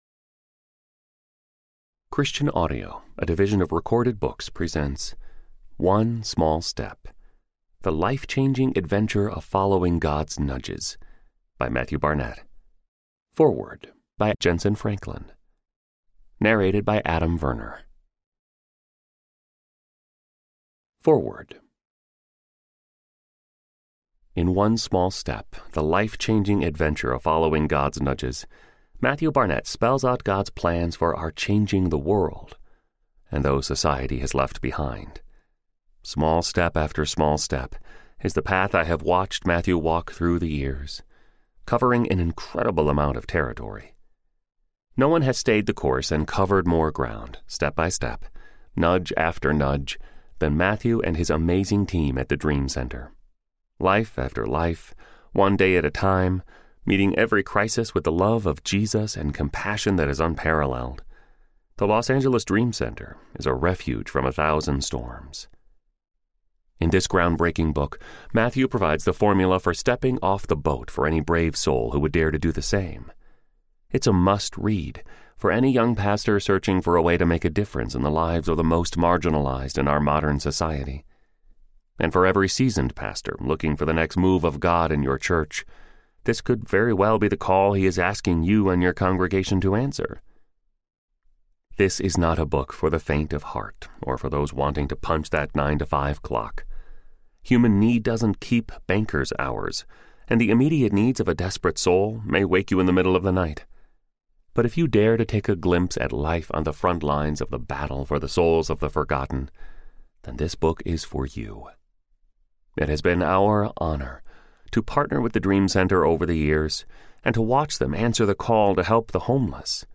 One Small Step Audiobook
5.5 Hrs. – Unabridged